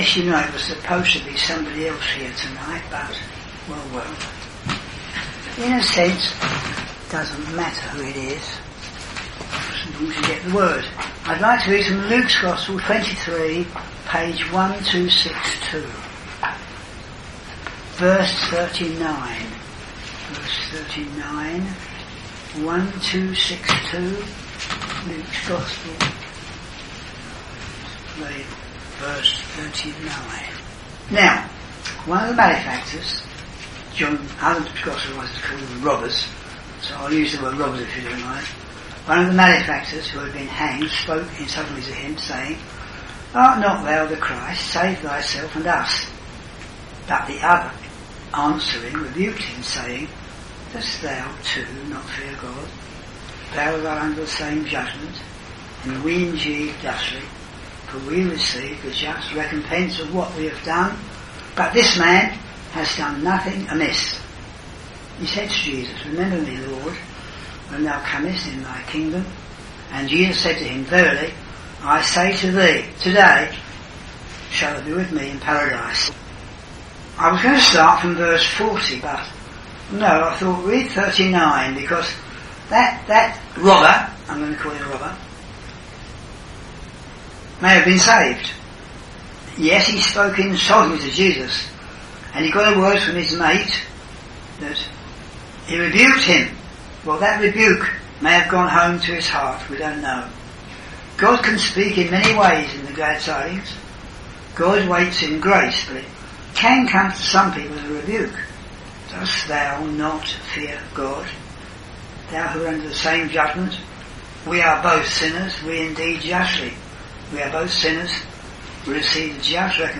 In this Gospel preaching, you will hear of two malefactors who were crucified with Jesus. One of the malefactors came to know Jesus as his personal Saviour simply by receiving the Gospel.